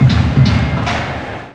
fanfare1.wav